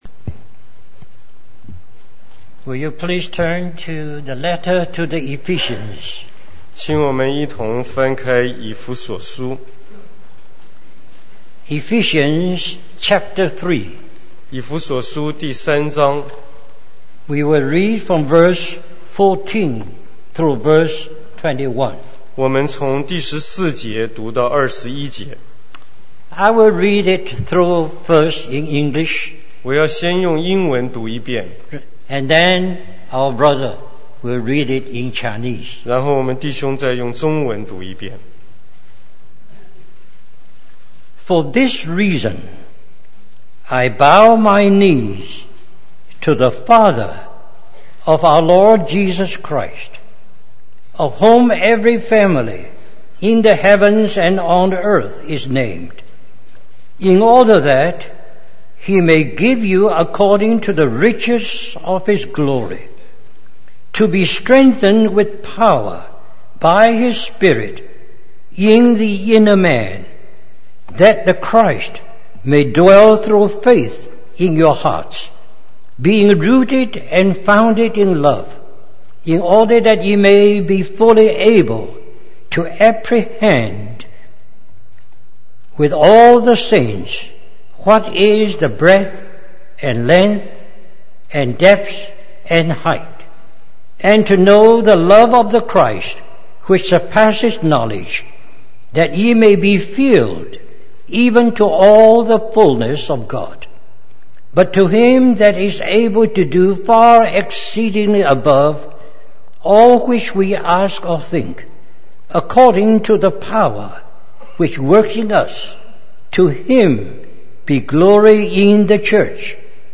In this sermon, the speaker shares a personal experience of struggling with the decision to follow the Lord and counting the cost. He reflects on the message he heard about forsaking all to follow Jesus and how it deeply impacted him.